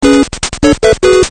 このBGM・SEが使用されているタイトルをお答えください。